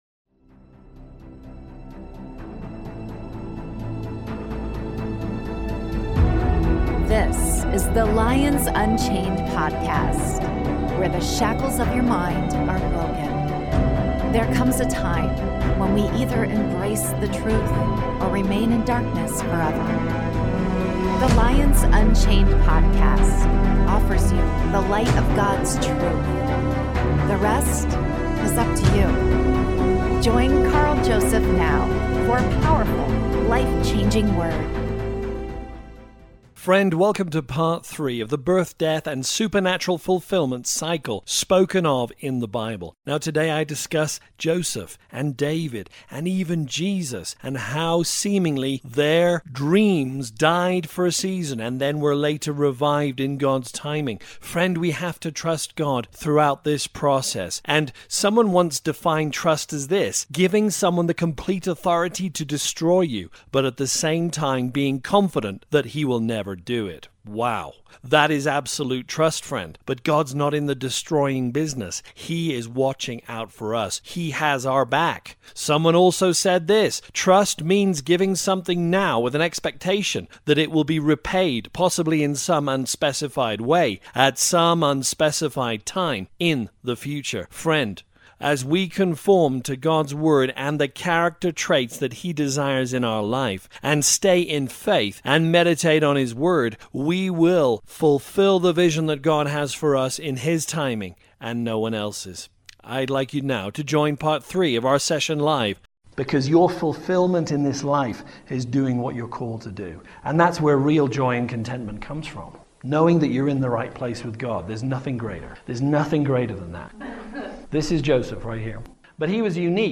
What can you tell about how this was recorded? Part 3 (LIVE)